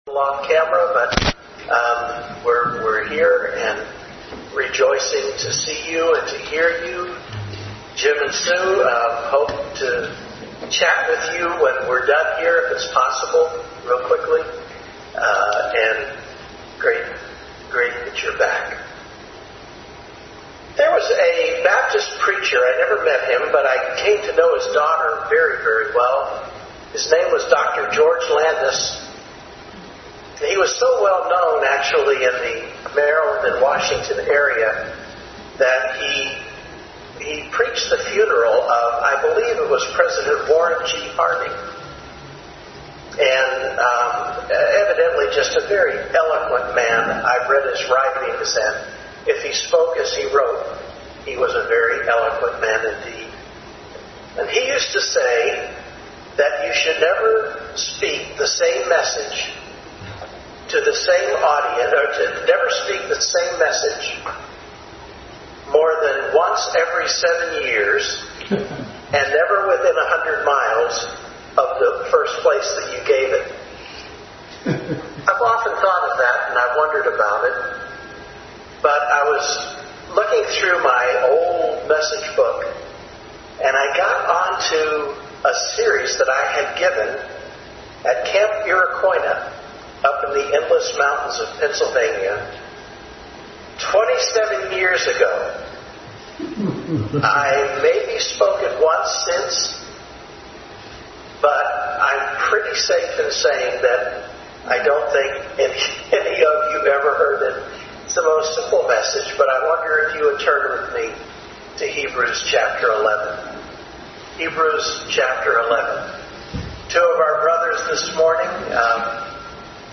Family Bible Hour Message.
Hebrews 11:1-40 Passage: Hebrews 11:1040 Service Type: Family Bible Hour Family Bible Hour Message.